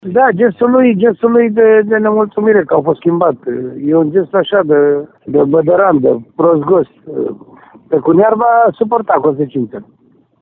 În minutul 63, Pedro Henrique a fost înlocuit de Octavian Drăghici, iar brazilianul a avut o reacție nervoasă la adresa antrenorului Ionuț Popa. Tehnicianul dă asigurări că atacantul va răspunde pentru gestul său: